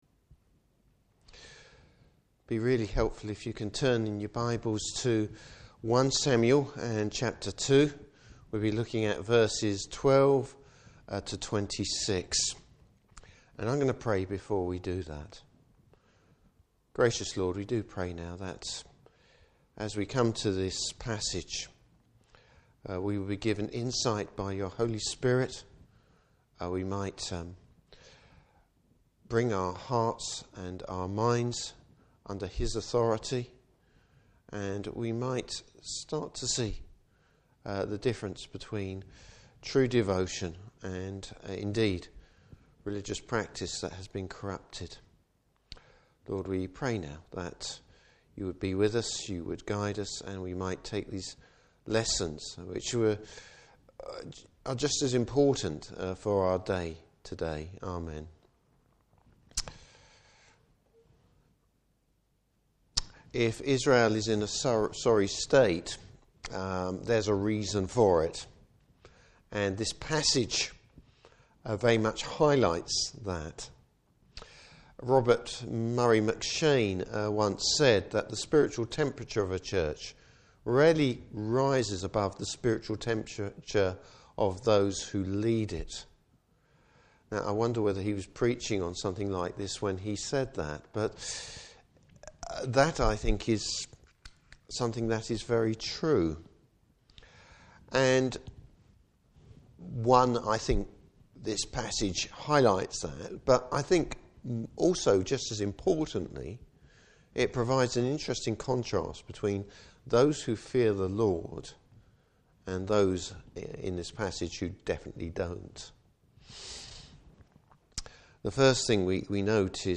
Service Type: Evening Service Corrupt religious leadership!